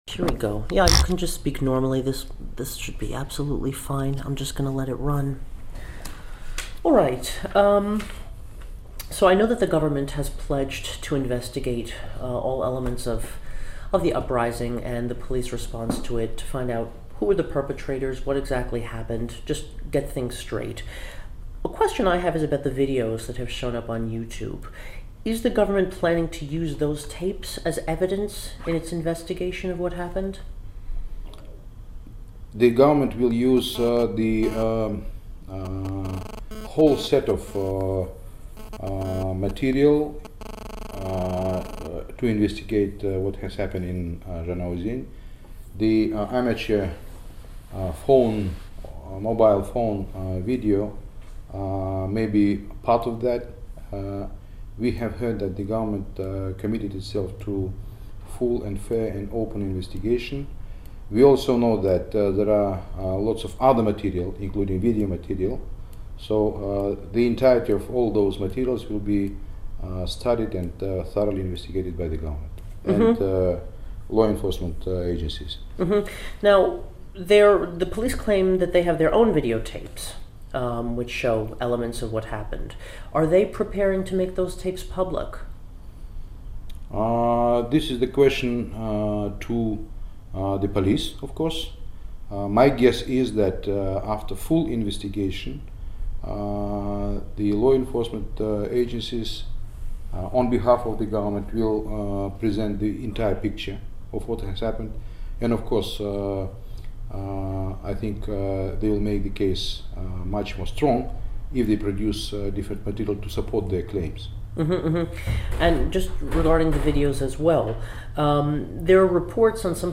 Интервью с послом Казахстана в США Ерланом Идрисовым